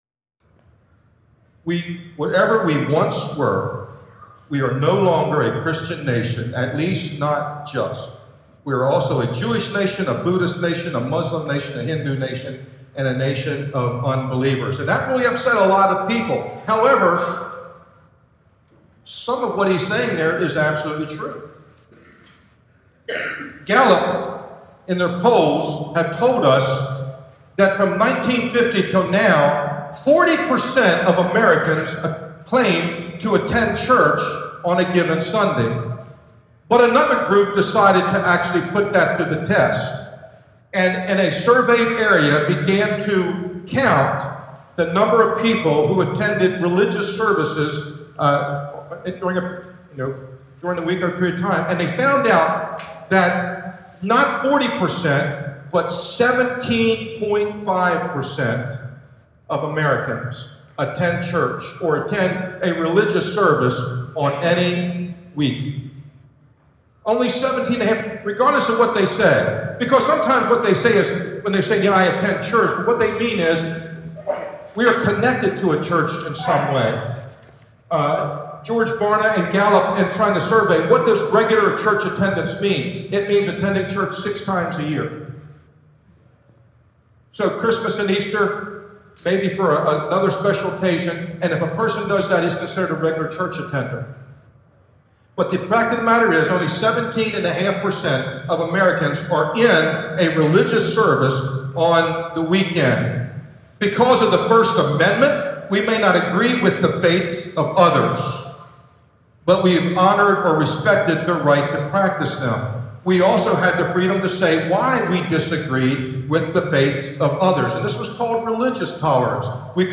NOTE* -Advanced apologies for the somewhat poor sound quality which is NOT typical of these posts, but it is audible and a very timely and profitable message.